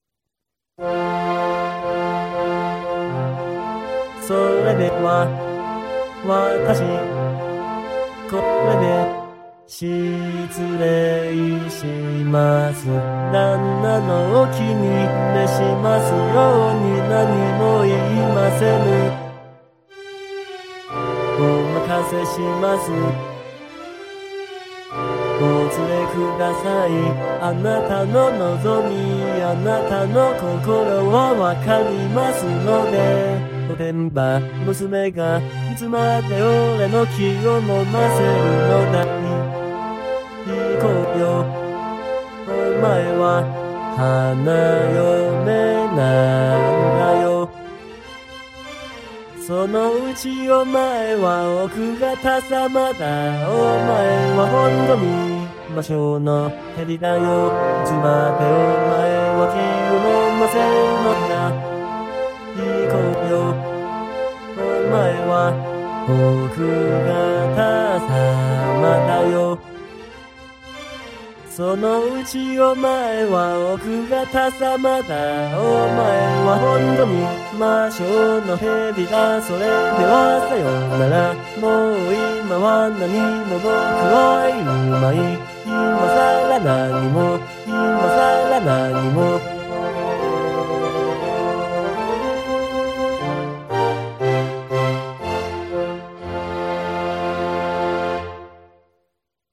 男声はGackpoidかKaitoを、女声は初音ミクNTを使いました。
管弦楽はGarritan Personal Orchestra5(VST)を使ってMP3形式で保存したものです。